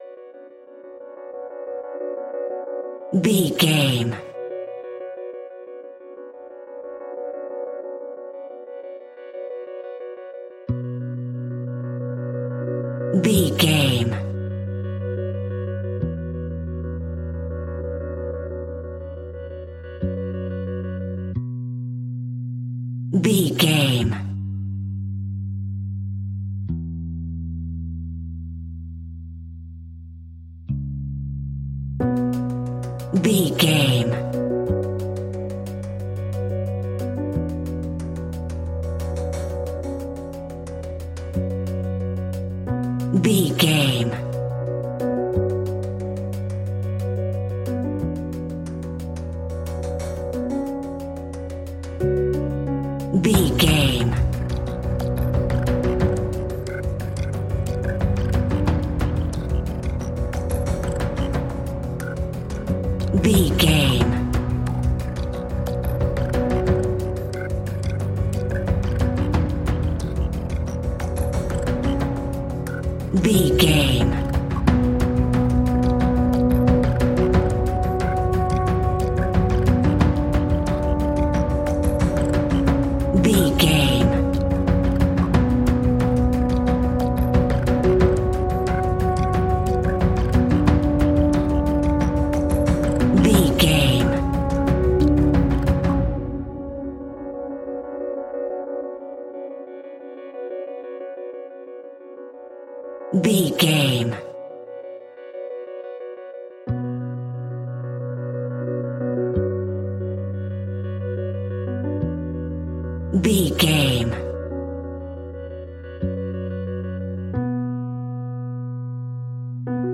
Aeolian/Minor
ominous
dark
haunting
eerie
synthesiser
drums
strings
horror music